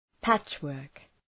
Προφορά
{‘pætʃ,wɜ:rk}